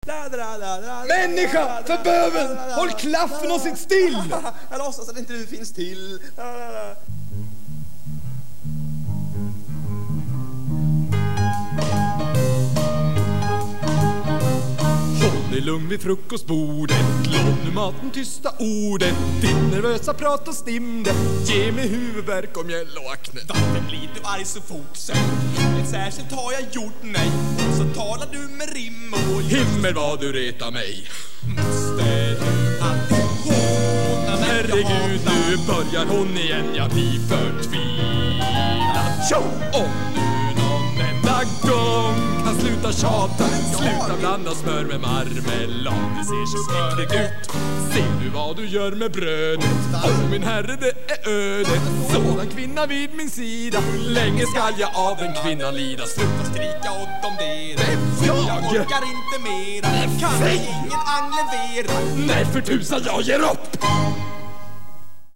Linköpings Studentspex 10-års jubileum 1989
Gnat-Duett i vilken Arthur och Guinivere skäller ut varandra vid frukostbordet.